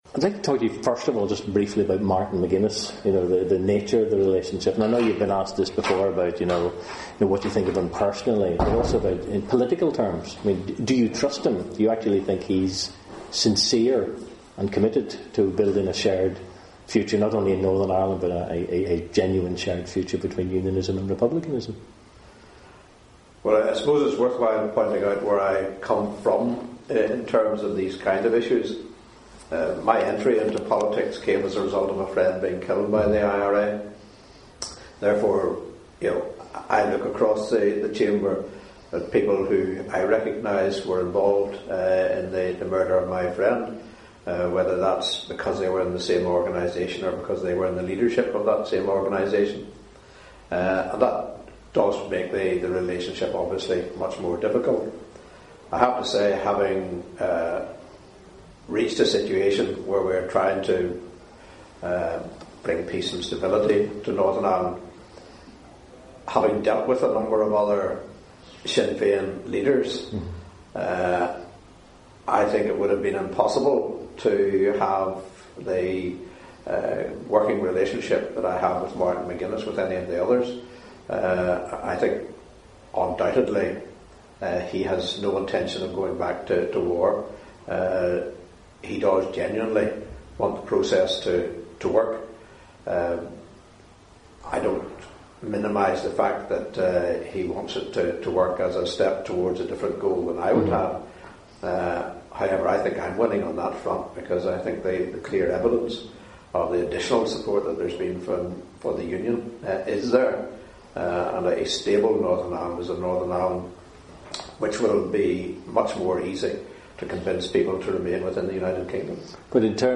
Second part of interview at http